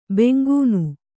♪ bengūnu